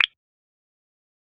normal_btn.bb20a2cc.mp3